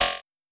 Modern UI SFX / AlertsAndNotifications
Error1.wav